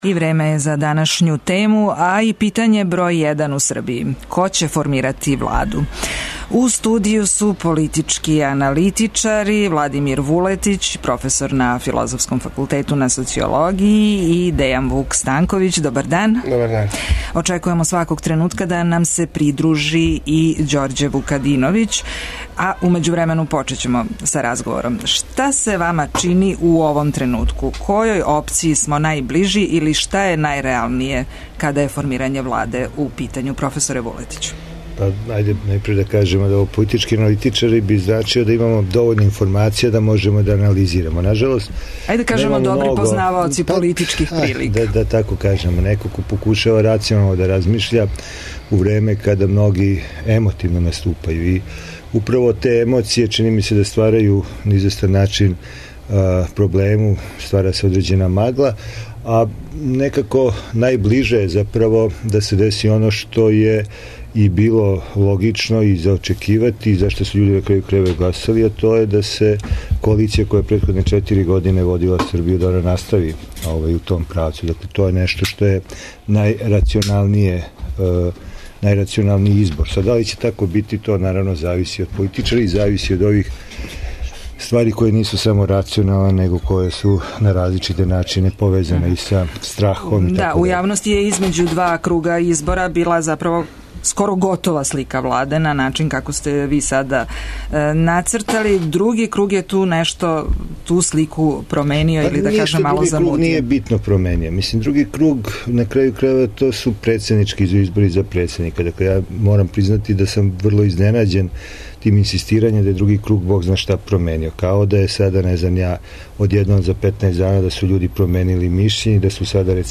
Говоре аналитичари